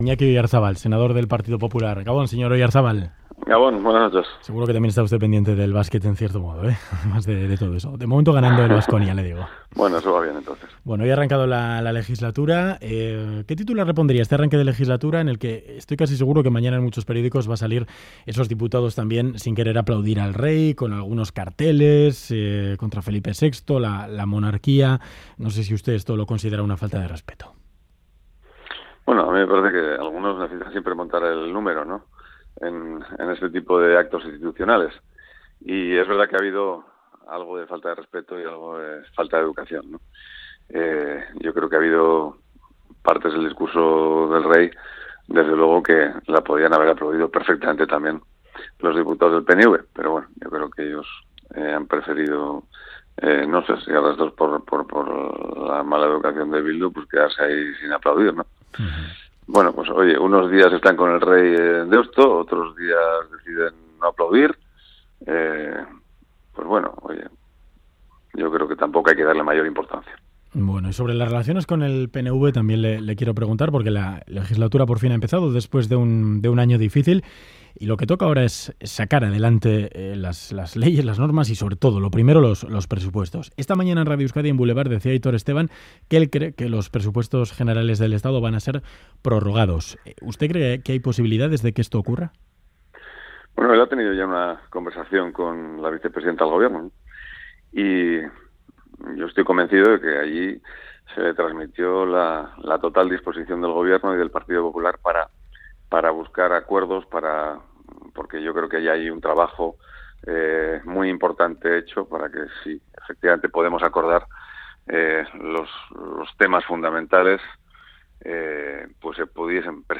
Audio: Hablamos con Iñaki Oyarzábal, senador del PP, sobre el inicio de legislatura y sobre los primeros acuerdos que necesita el gobierno de Rajoy